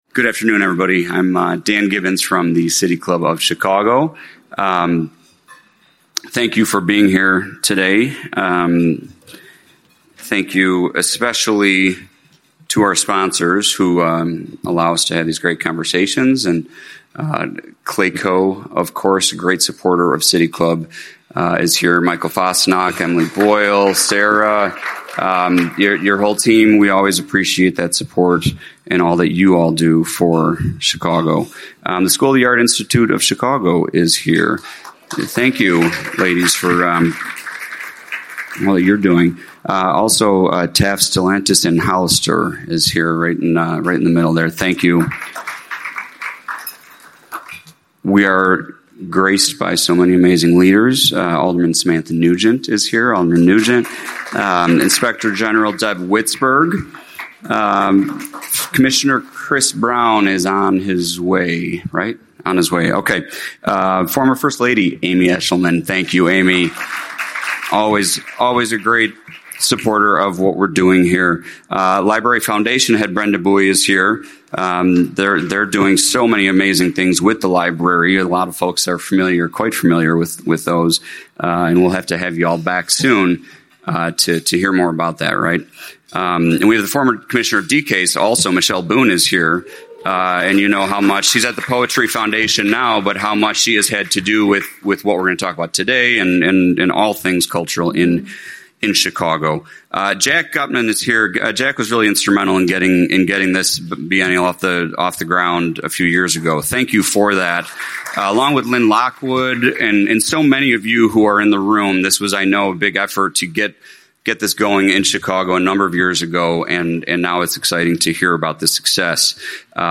City Club event description